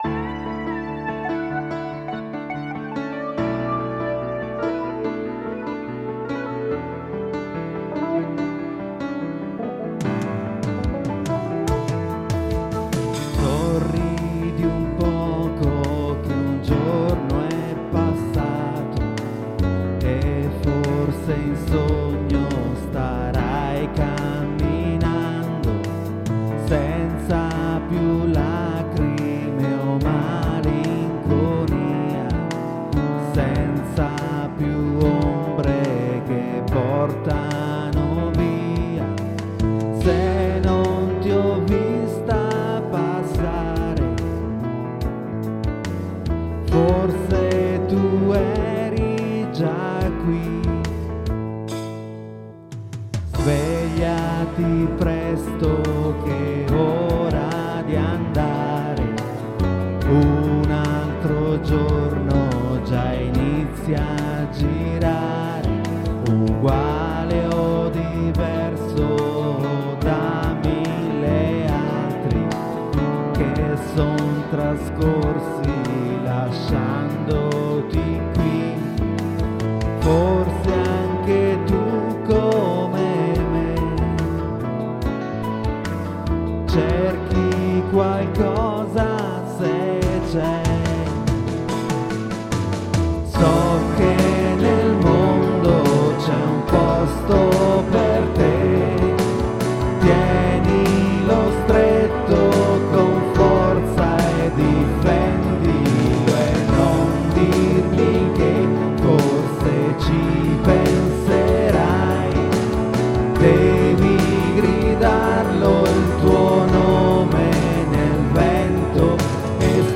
• Multitrack Recorder Zoom MRS-4
• Mic AKG D 40 S